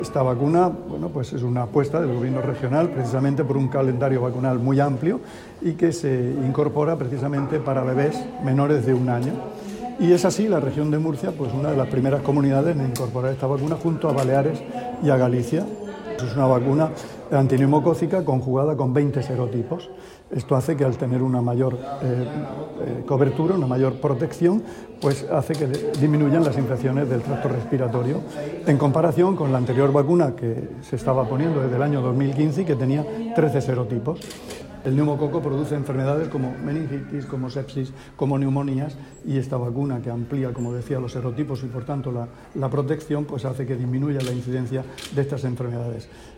Declaraciones del consejero de Salud, Juan José Pedreño, sobre la nueva vacuna contra el neumococo. [mp3]